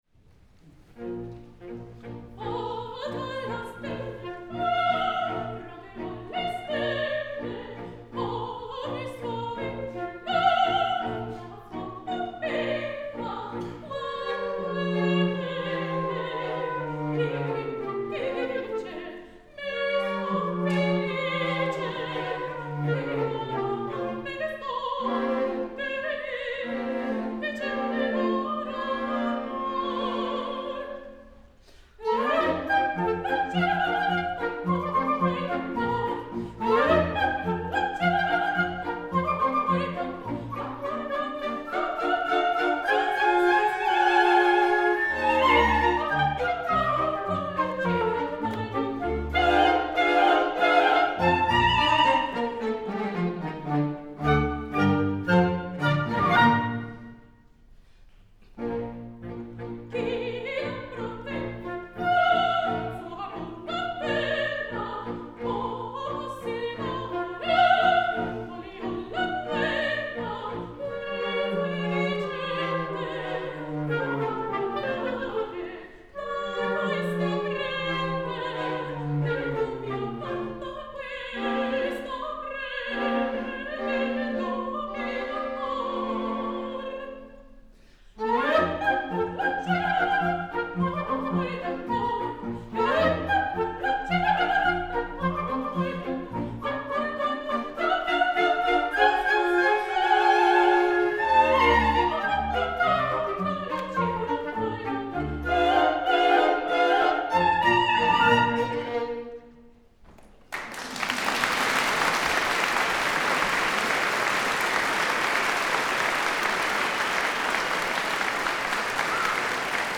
Denna inspelning gjordes med rumsmickar och jag ska testa i fyrkanal och se vad det ger.
En ny mix på samma aria: